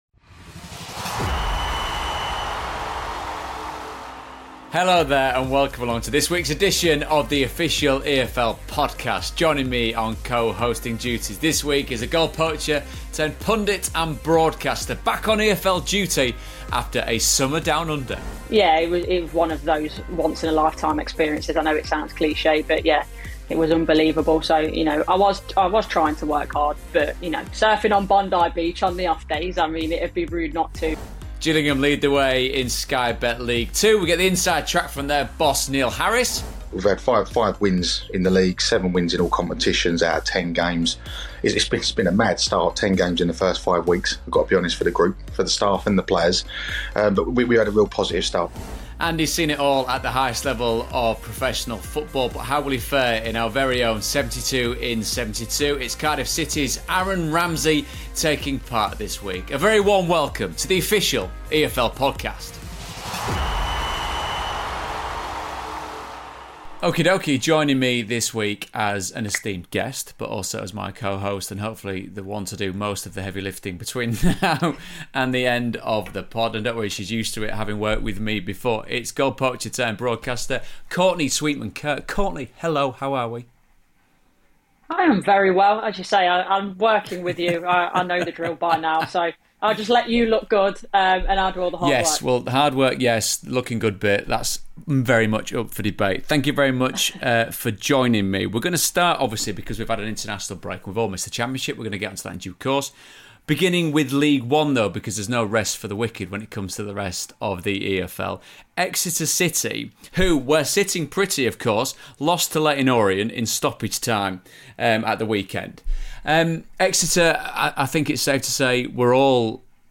On this week's Official EFL Podcast, David Prutton is joined by Courtney Sweetman-Kirk for some expert punditry and analysis on all things EFL.